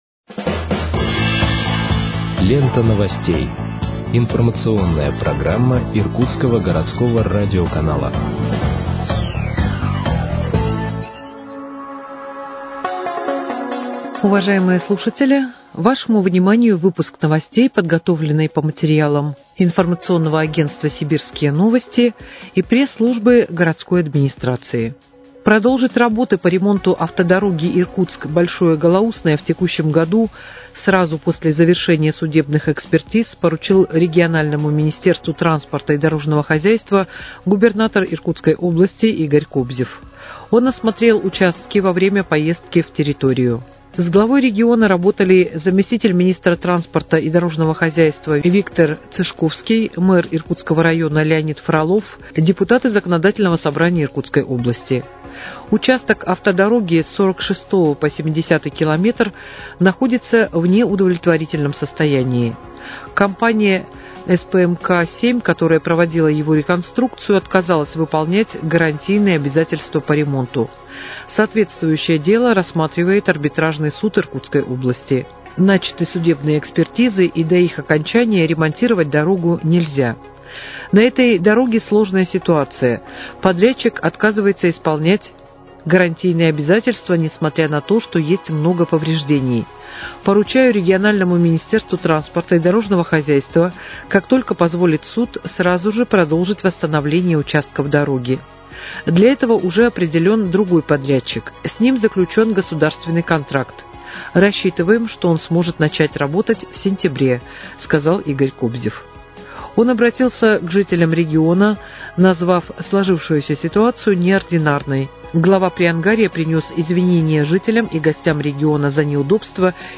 Выпуск новостей в подкастах газеты «Иркутск» от 31.07.2025 № 1